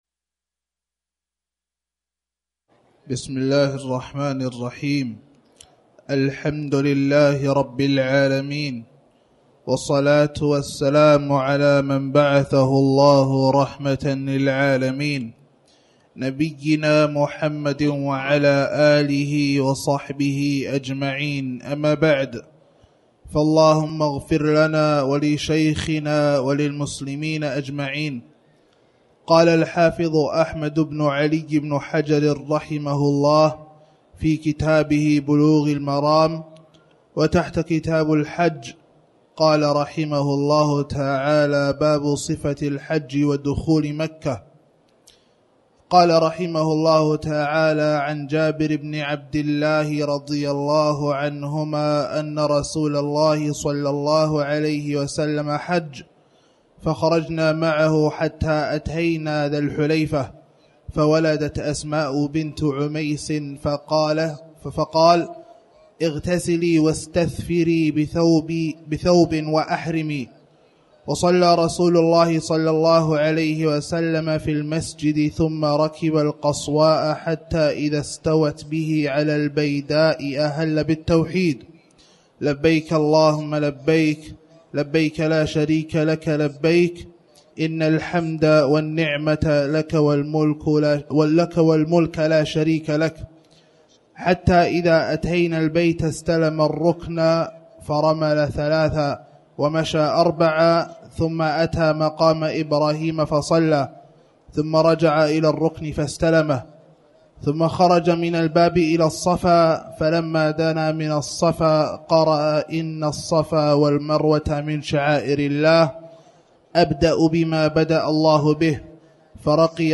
تاريخ النشر ٣ ذو القعدة ١٤٣٨ هـ المكان: المسجد الحرام الشيخ